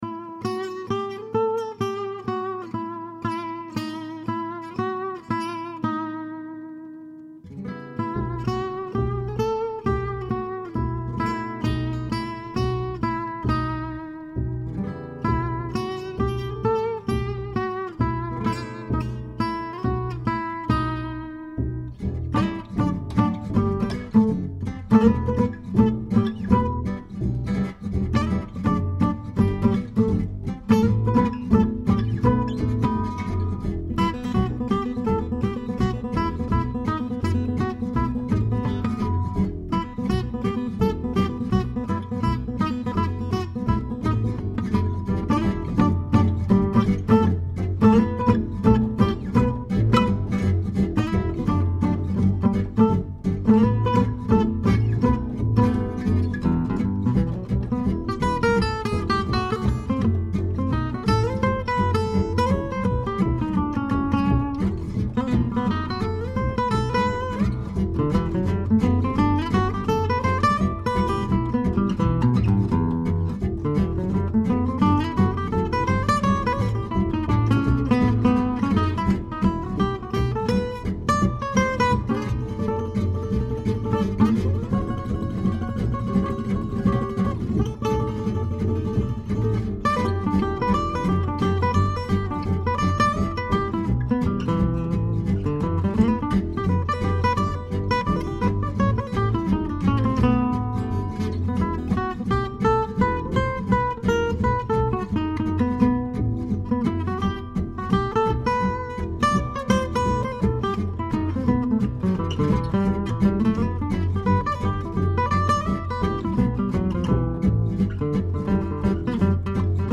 Je fais juste l'accompagnement, à 50km du micro. :-)